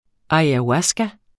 Udtale [ ɑjaˈwasga ]